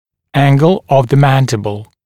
[‘æŋgl əv ðə ‘mændɪbl][‘энгл ов зэ ‘мэндибл]угол нижней челюсти